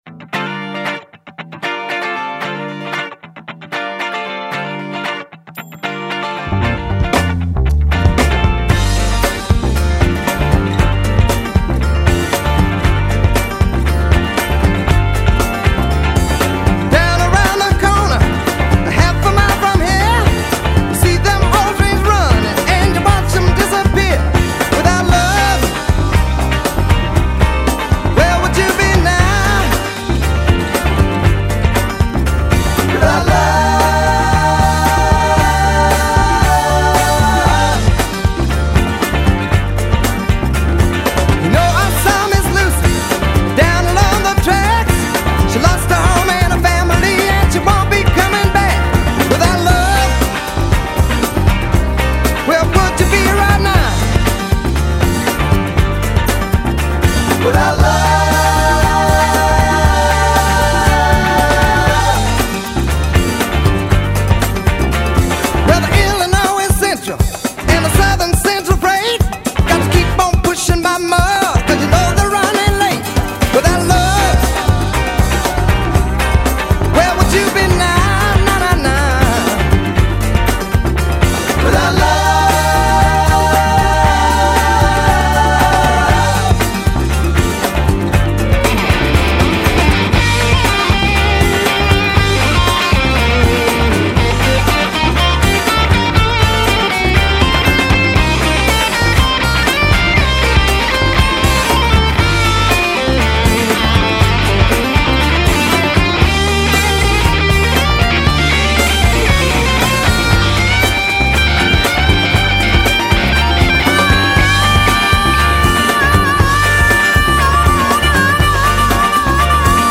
gitaar
backings
percussie